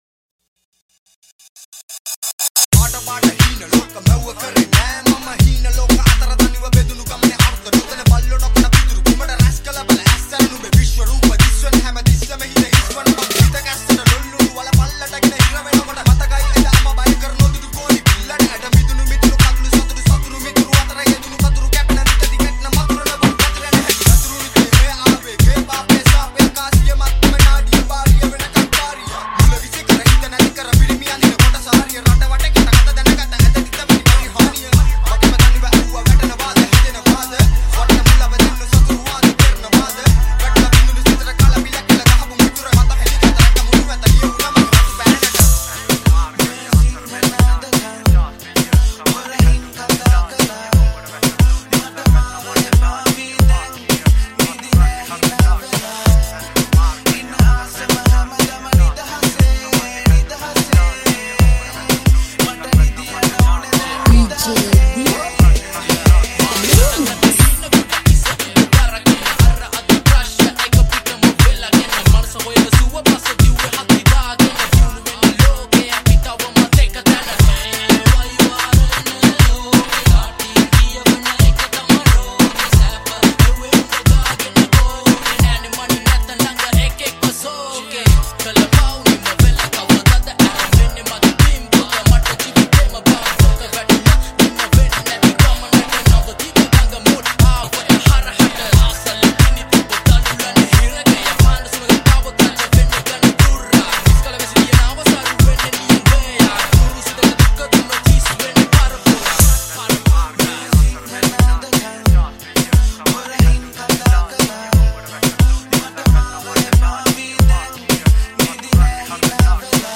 High quality Sri Lankan remix MP3 (6.8).